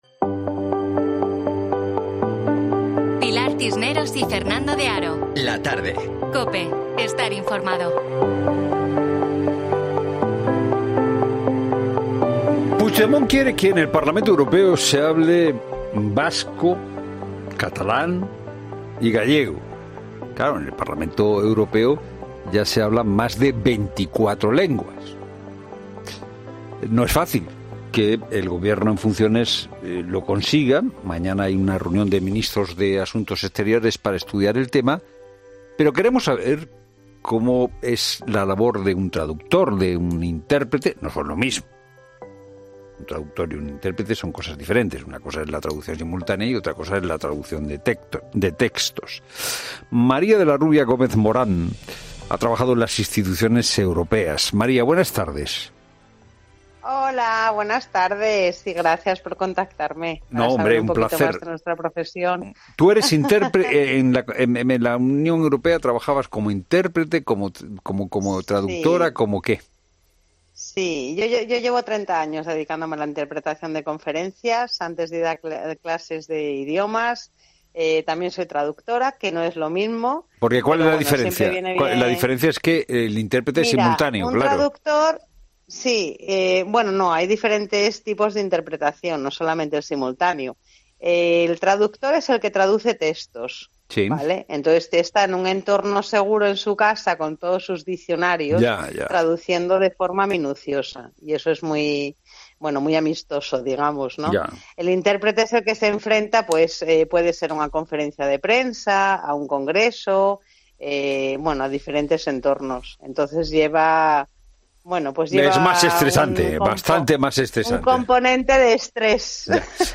En 'La Tarde' hablamos con una intérprete que ha trabajado en las instituciones europeas y nos explica cómo se trabaja y en qué cambiaría si se aceptase la propuesta del Gobierno